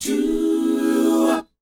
DOWOP F#4C.wav